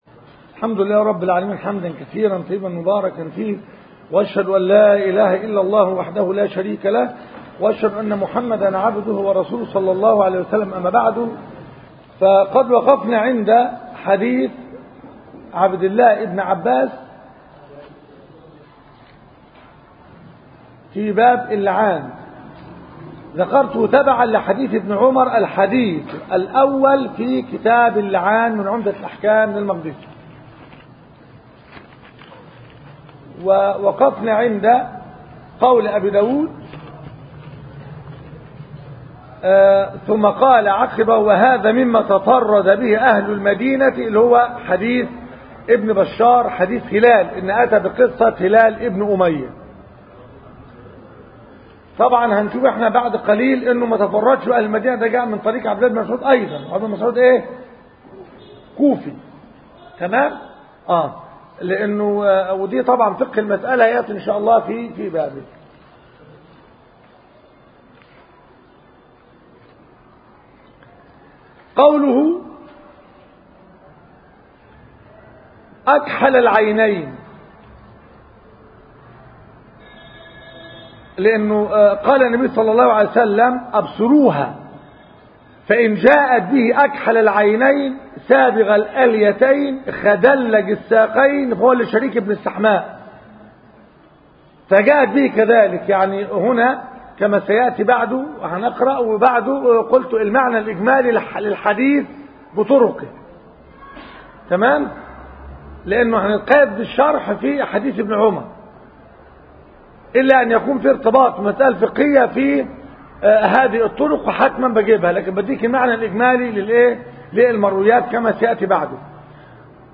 كتاب اللعان - المحاضرة الثانية